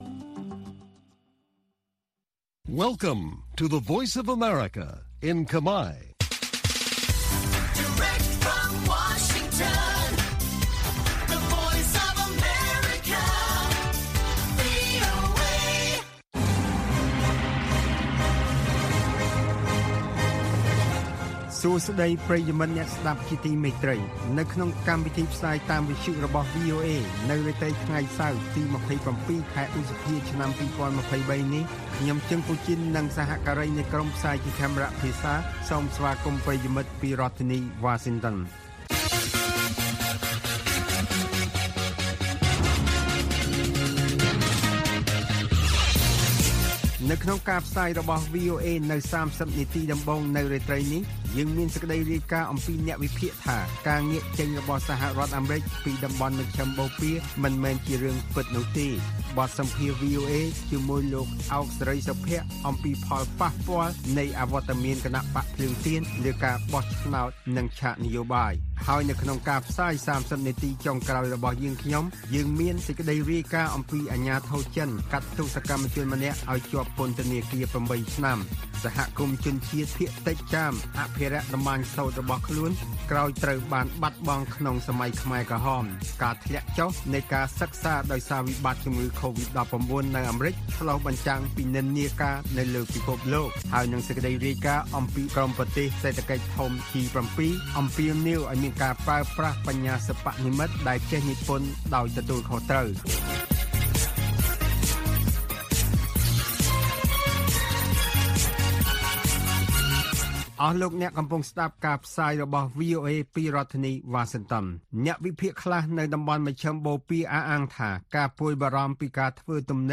ព័ត៌មាននៅថ្ងៃនេះមានដូចជា ក្រុមប្រទេស G7 អំពាវនាវឲ្យមានការប្រើប្រាស់បញ្ញាសិប្បនិម្មិតដែលចេះនិពន្ធ«ដោយទទួលខុសត្រូវ»។ បទសម្ភាសន៍